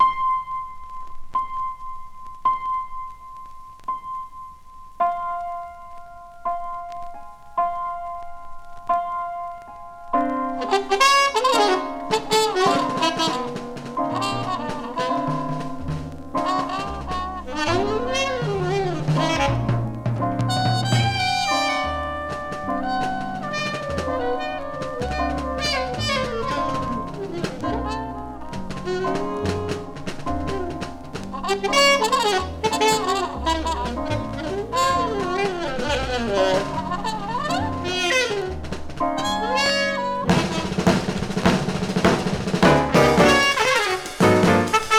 Jazz, Modal　USA　12inchレコード　33rpm　Stereo